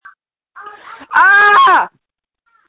• When you call, we record you making sounds. Hopefully screaming.